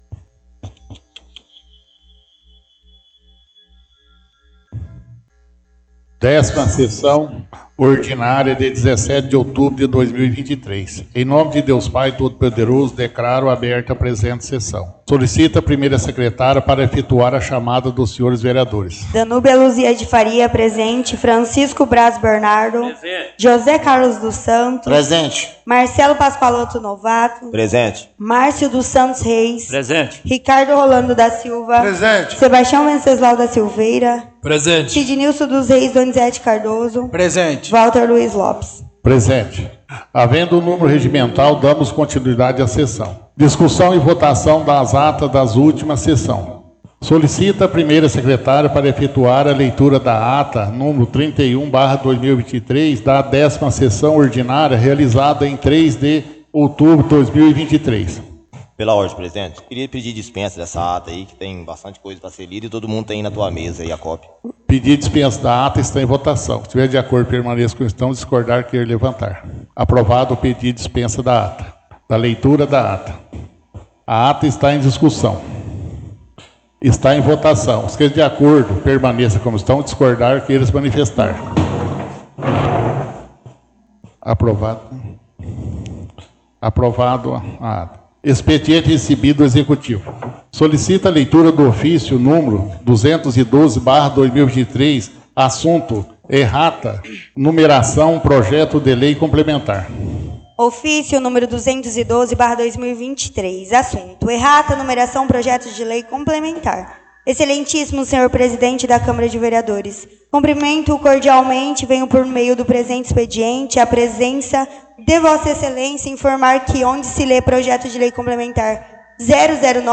Áudio 17ª Sessão Ordinária – 17/10/2023